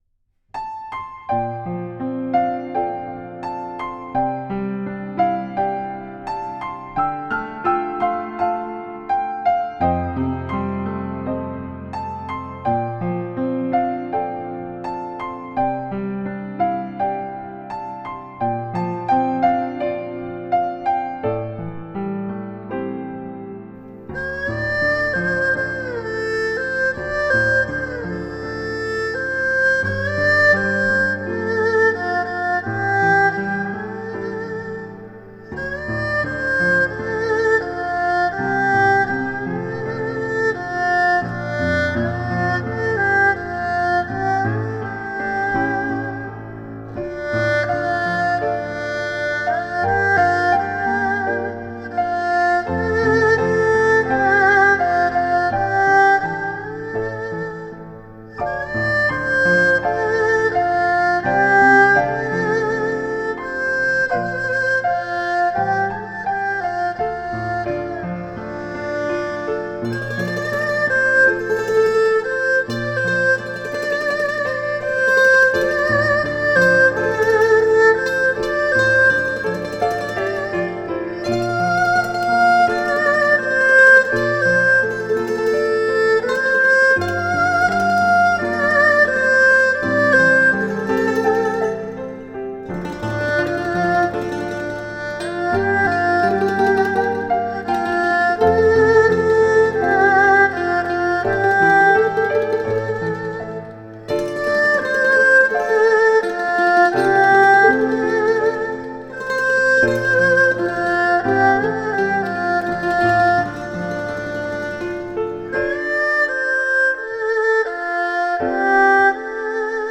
以轻盈的钢琴展开音乐
二胡如泣如诉与钢琴的清亮形成情感的对比
钢琴
二胡
琵琶
尺八
古筝
吉他